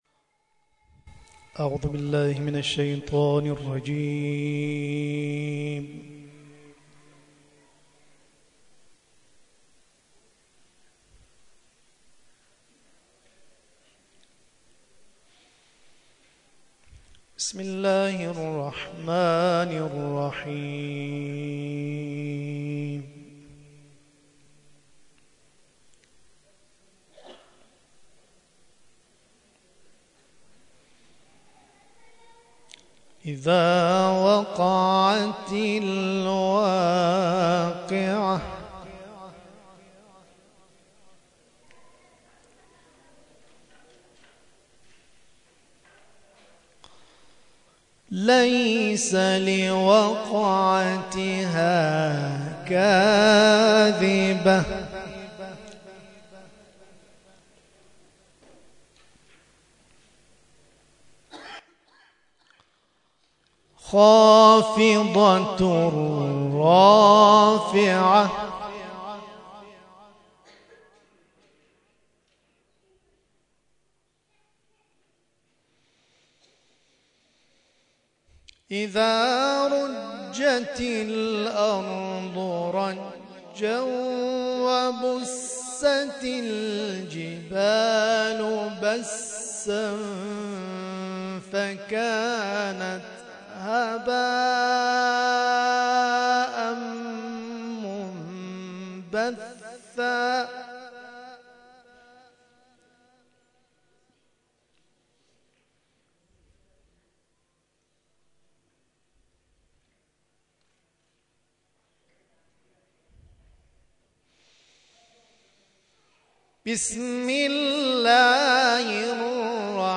گروه جلسات و محافل: کرسی‌های تلاوت نفحات‌القرآن، در هفته گذشته با هدف جمع آوری کمک جهت زلزله زدگان کرمانشاه، با حضور قاریان ممتاز در مساجد و حسینیه‌های تهران و شهرری برگزار شد.